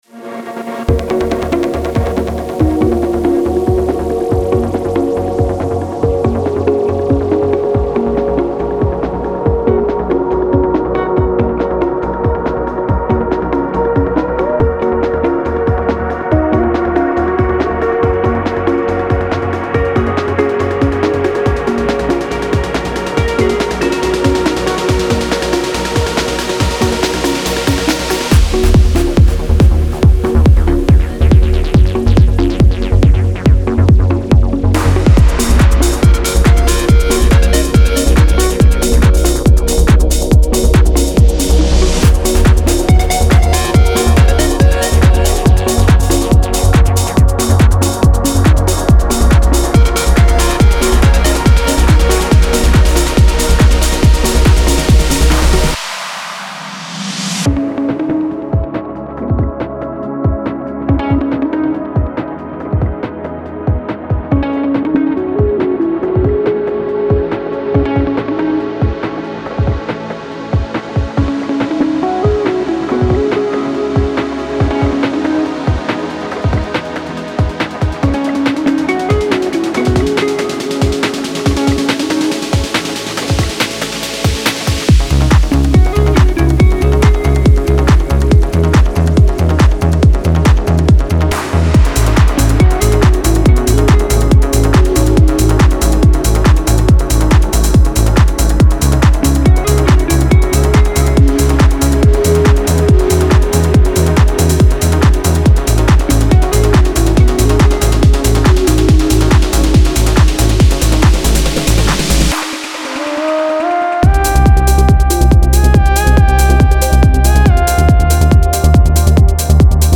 Genre:Trance
デモサウンドはコチラ↓
140 BPM